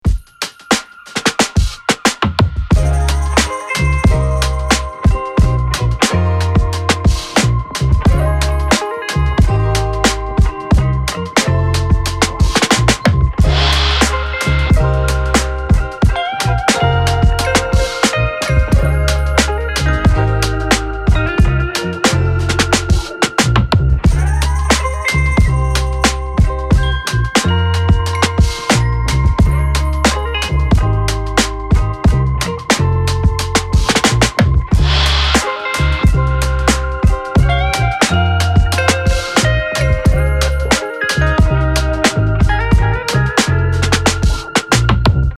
royalty-free drum breaks, percussion loops and one-shots
Explosive Drum Sounds